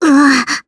Gremory-Vox_Damage_jp_03.wav